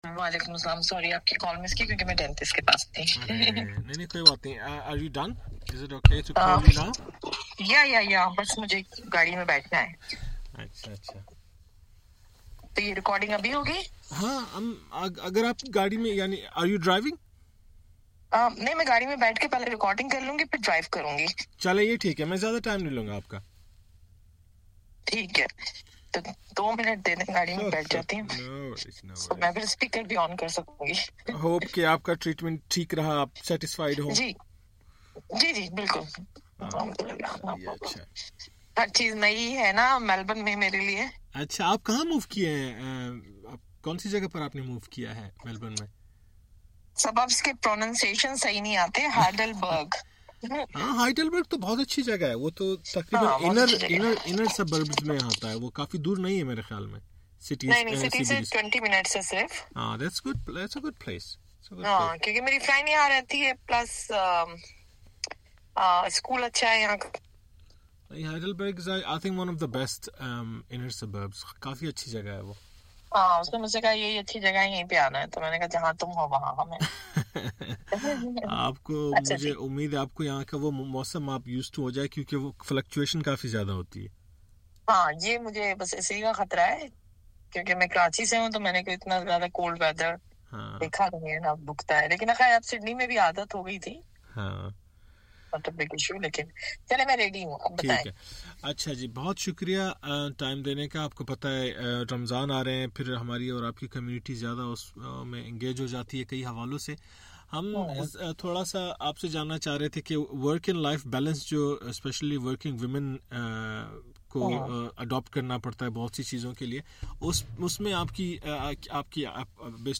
آج کے اس خصوصی انٹرویو میں